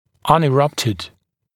[ˌʌnɪ’rʌptɪd][ˌани’раптид]непрорезавшийся